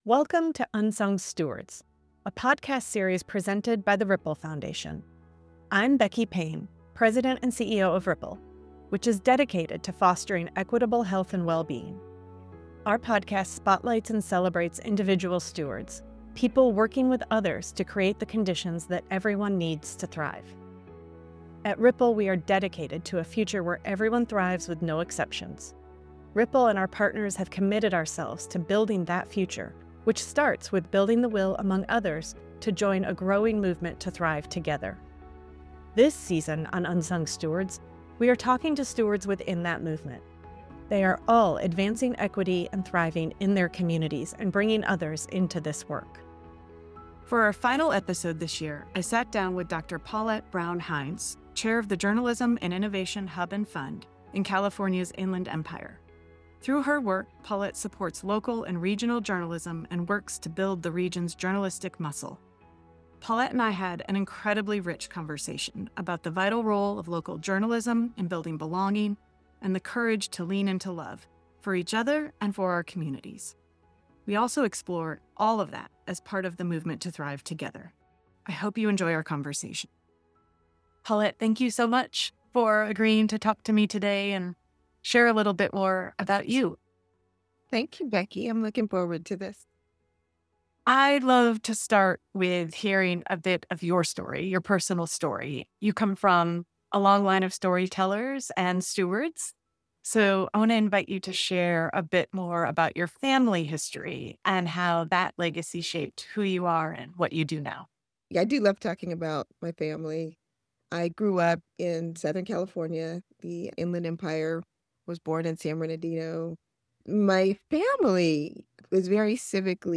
This conversation highlights the power of local media, the importance of belonging, and the role storytelling plays in building belonging and love for one's place.